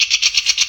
spider.ogg